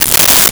Paper Tear 08
Paper Tear 08.wav